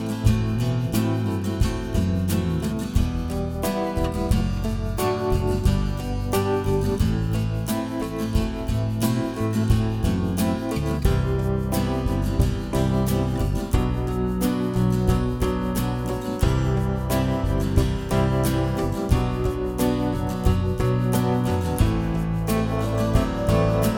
No Harmony Pop (1990s) 2:49 Buy £1.50